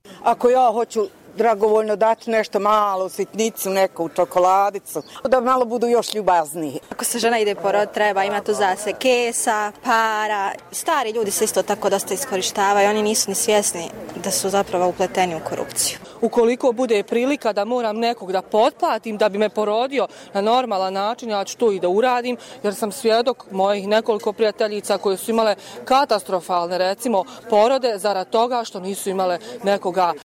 Građani o korupciji